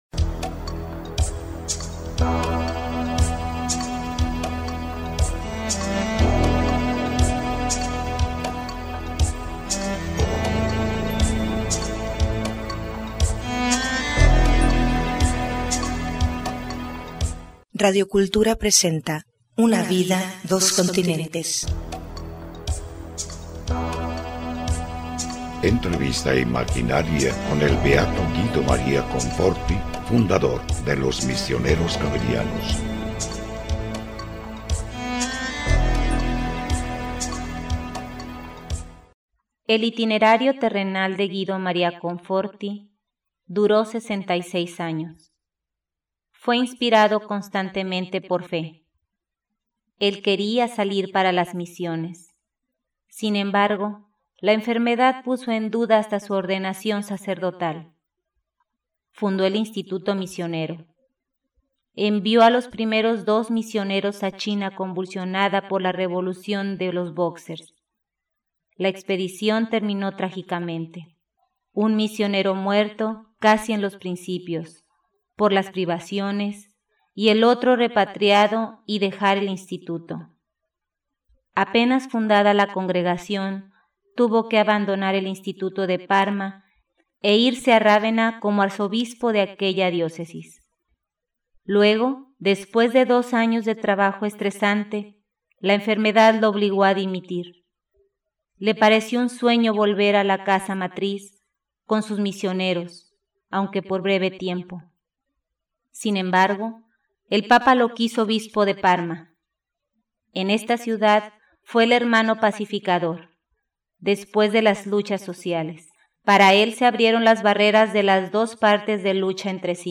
Entrevista imaginaria con san Guido Maria Conforti, Obispo de Rávena y Parma, fundador de los Misioneros Xaverianos.